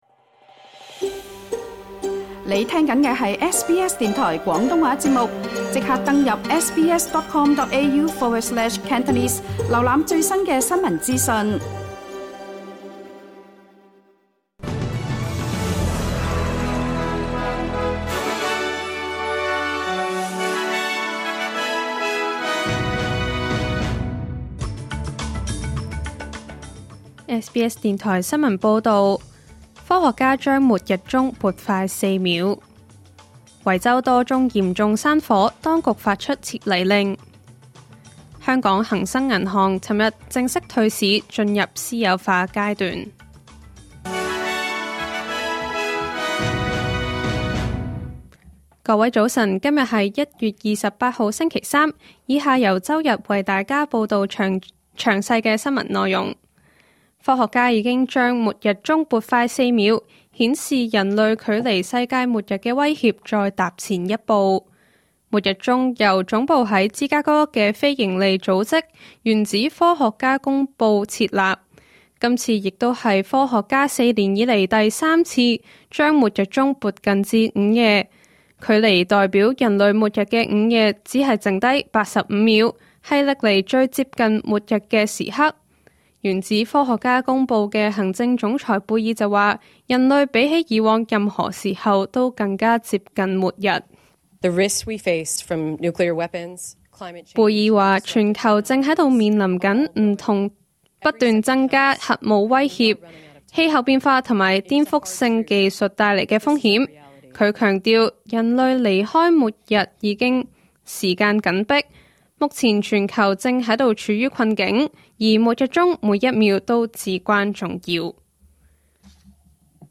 2026年1月28日SBS廣東話節目九點半新聞報道。